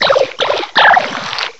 cry_not_sliggoo.aif